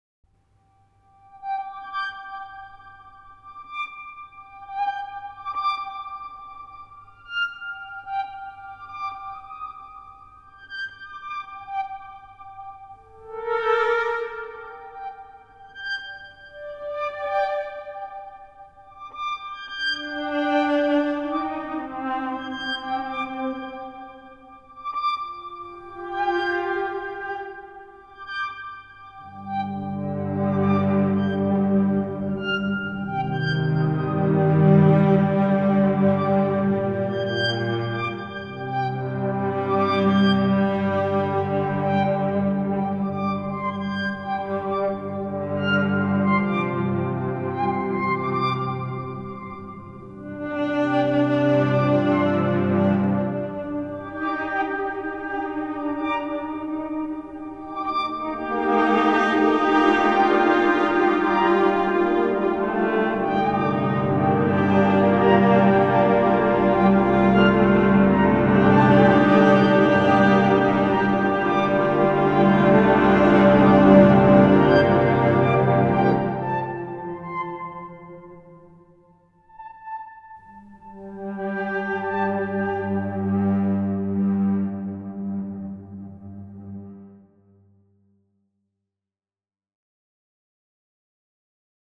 This meticulous process transformed the strings into a synth-like texture while preserving their organic richness creating something truly distinctive: a synth made from strings.
Highlights include the “Low Tide” patch, a customer favorite known for its evocative, immersive quality, and “Bristeacha,” which captures the players’ improvisations as they ascend the scale of their instruments.
RS MIX – the re-amped string synth
Low Tide RS MIX (string synth) Violin + Cello